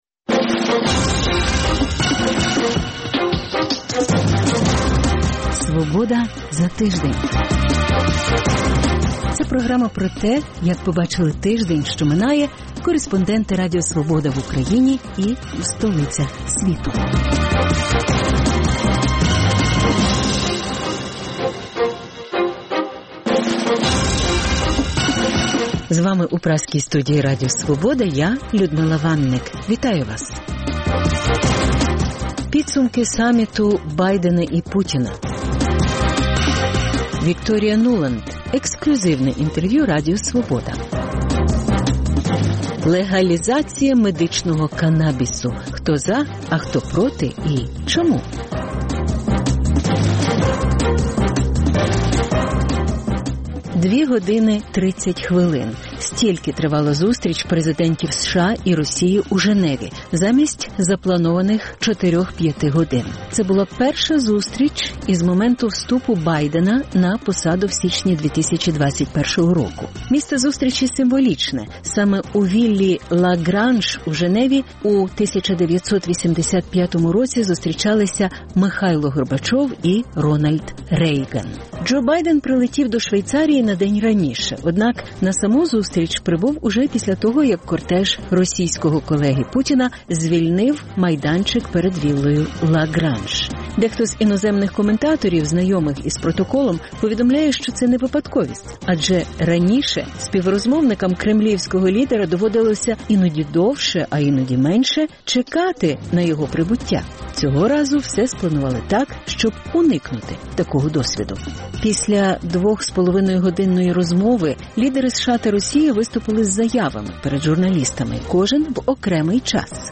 Підсумки саміту Байдена і Путіна. Вікторія Нуланд – ексклюзивне інтерв'ю. Росія стягує війська на Захід – НАТО тренується в Європі.